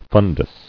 [fun·dus]